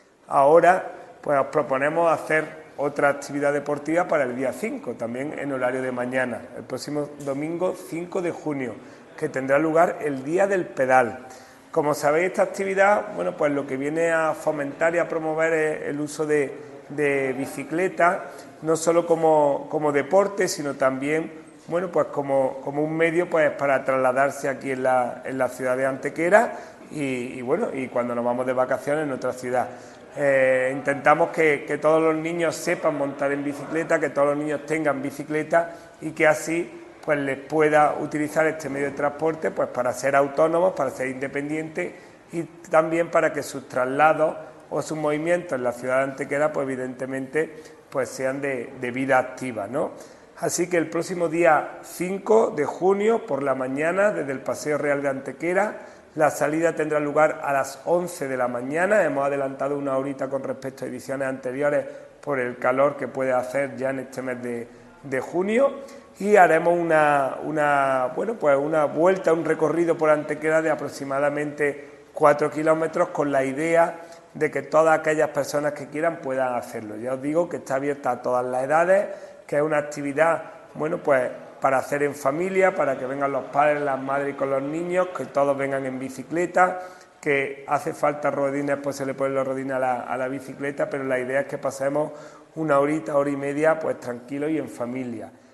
El teniente de alcalde Juan Rosas, que ha presentado dicha actividad en rueda de prensa, ha destacado que se trata de una iniciativa que trata de promover el uso de la bicicleta no sólo como deporte, sino como un medio para trasladarse por la ciudad: "El uso de la bicicleta nos confiere la capacidad, desde muy jóvenes, de ser autónomos e independientes, pudiendo realizar traslados mediante actividades de vida activa dentro de la ciudad".
Cortes de voz